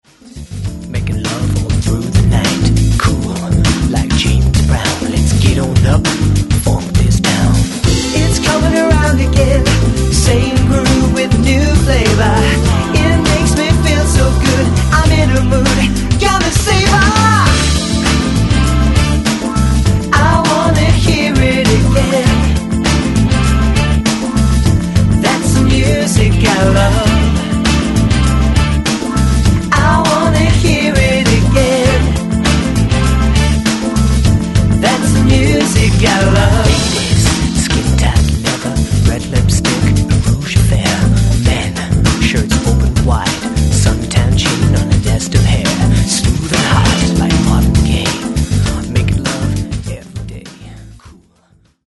House Version